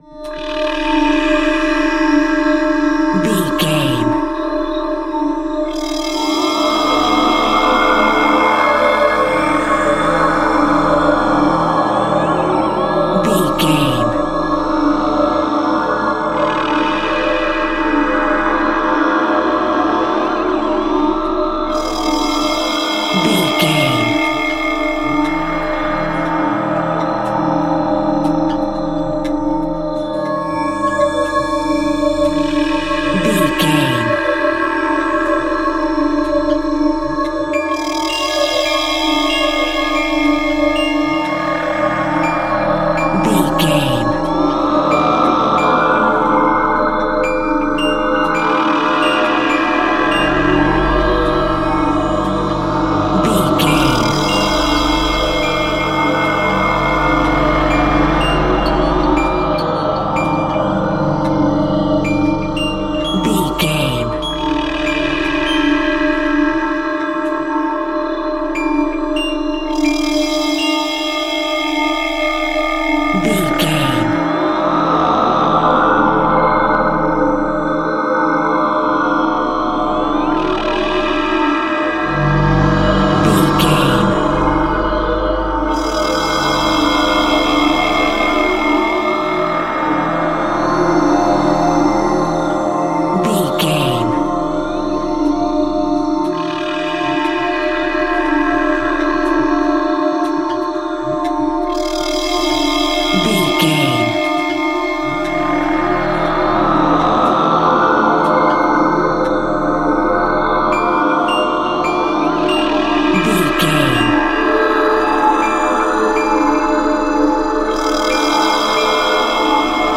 In-crescendo
Thriller
Aeolian/Minor
tension
ominous
dark
suspense
haunting
eerie
Horror synth
Horror Ambience
electronics
synthesizer